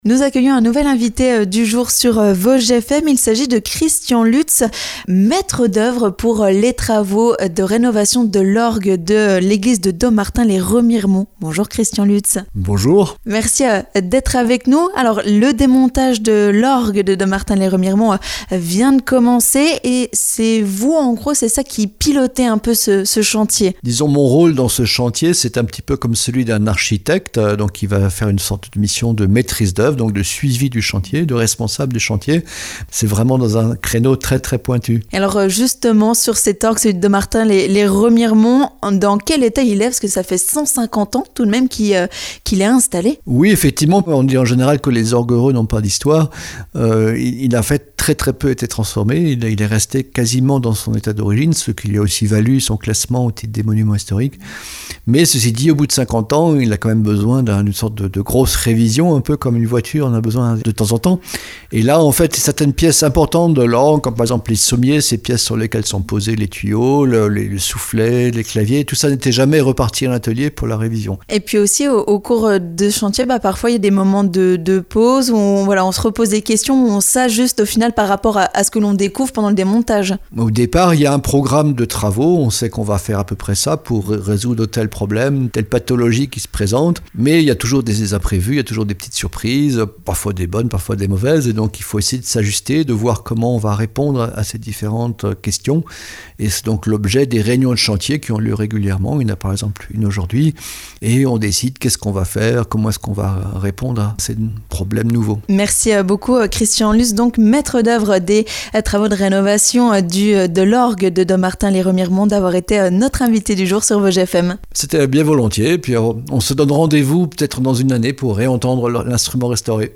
3. L'invité du jour